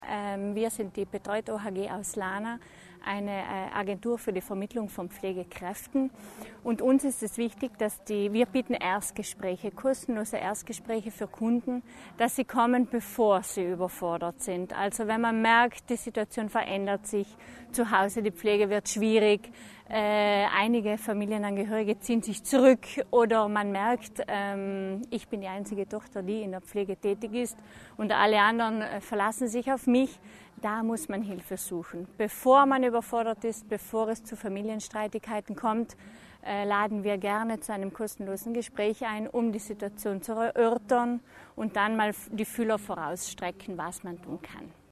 Weltalzheimertag 2021: Infomesse – Alzheimer Verein Südtirol Alto Adige
Interviews